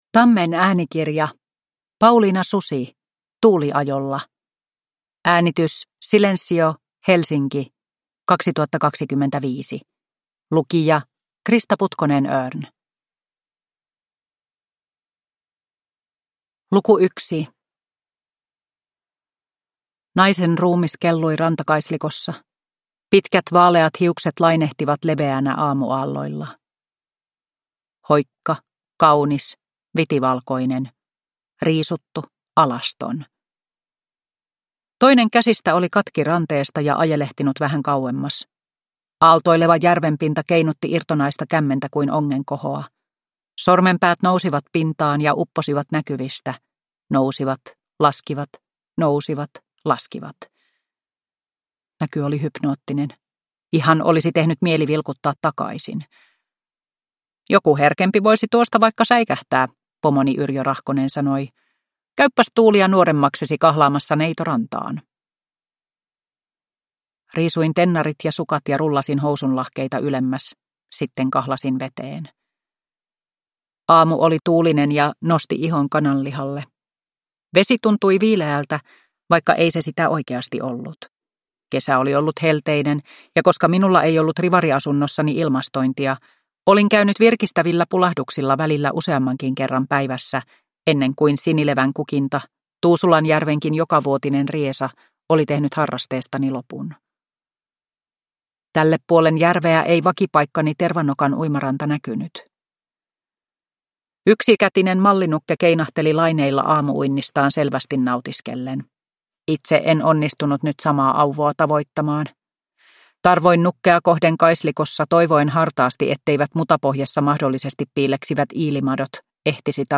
Tuuliajolla (ljudbok) av Pauliina Susi